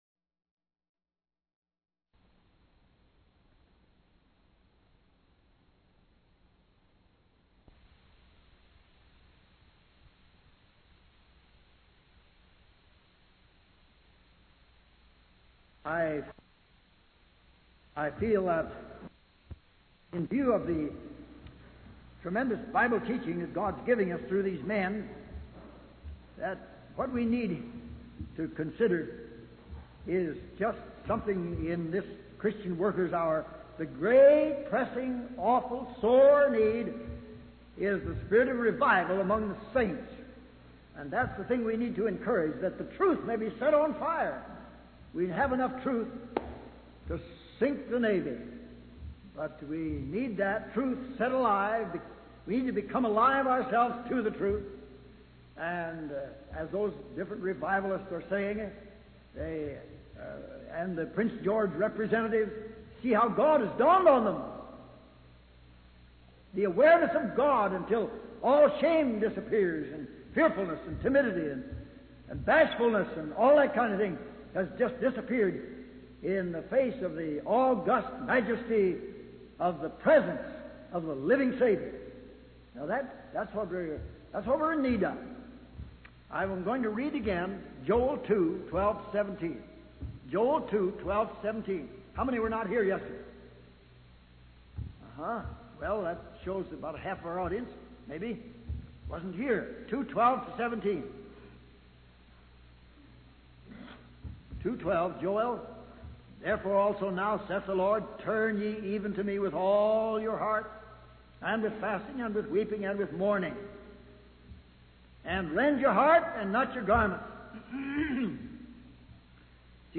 In this sermon, the speaker expresses concern about the state of the country and the leadership of the next generation. He believes that without revival or a spiritual awakening, there could be a bloody revolution within the next 10 to 20 years.